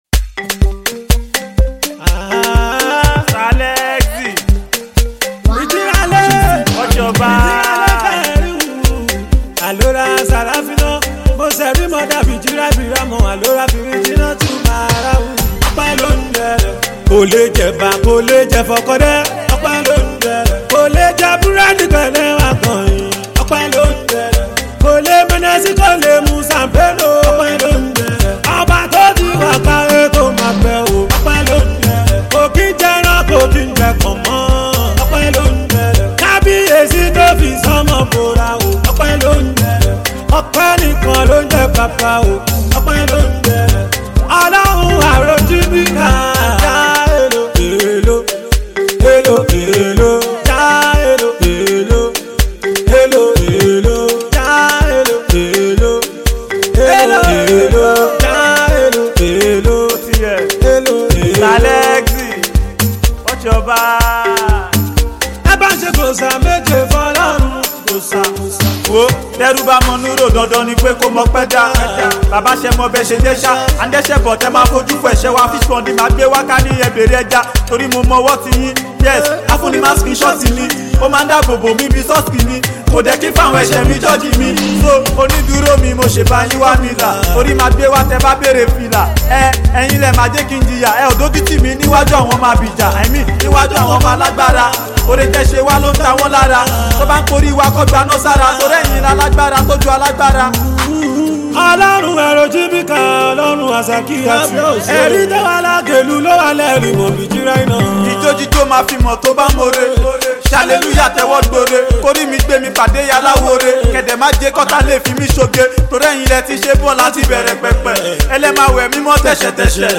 rapper
unrestrained and impassioned effort